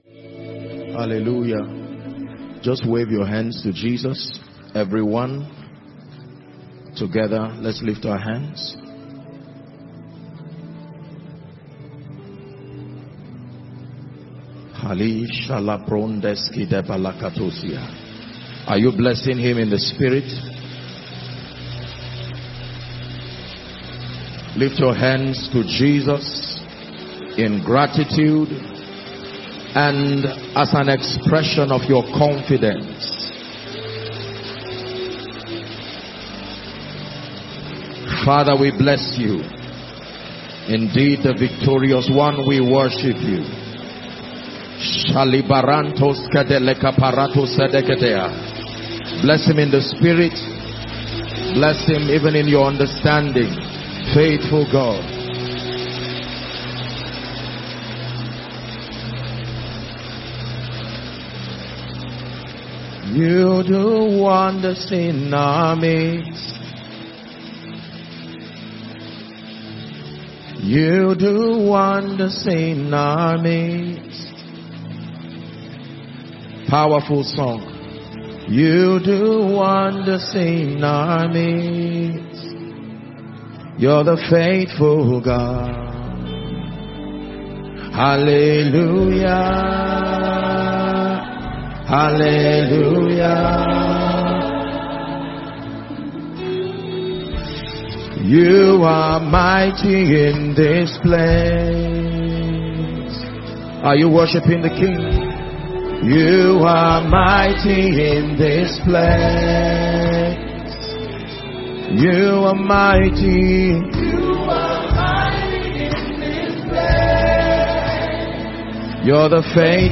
Sunday audio teaching